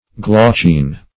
Search Result for " glaucine" : The Collaborative International Dictionary of English v.0.48: Glaucine \Glau"cine\, n. (Chem.)